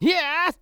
CK蓄力06.wav
CK蓄力06.wav 0:00.00 0:00.62 CK蓄力06.wav WAV · 53 KB · 單聲道 (1ch) 下载文件 本站所有音效均采用 CC0 授权 ，可免费用于商业与个人项目，无需署名。
人声采集素材/男2刺客型/CK蓄力06.wav